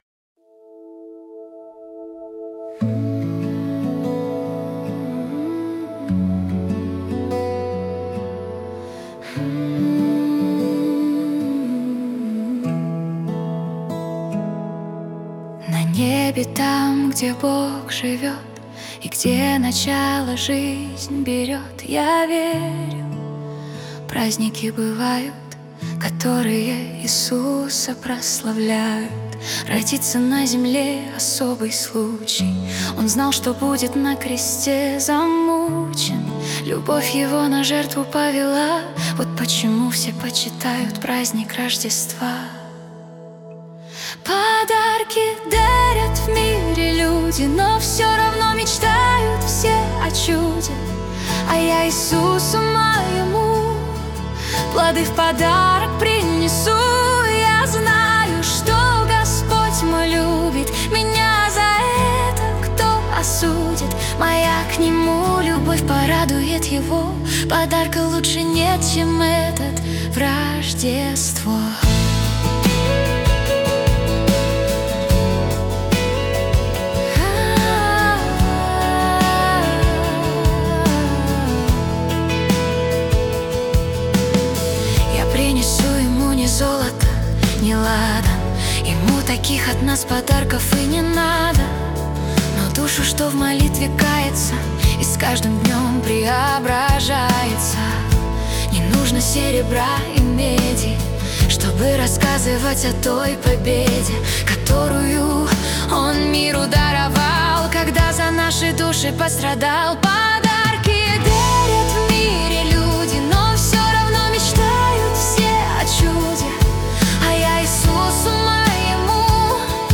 песня ai
228 просмотров 608 прослушиваний 60 скачиваний BPM: 74